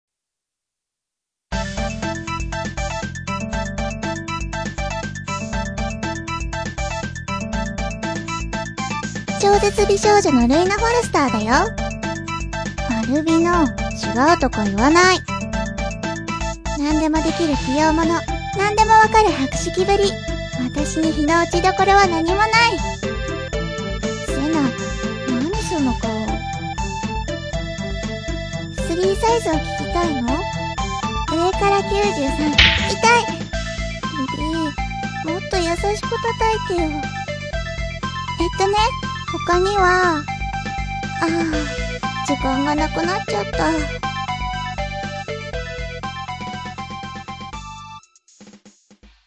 必要な事以外ほとんど喋らない無口な子で、消えてしまいそうな儚い喋り方だが、
自己紹介ボイス"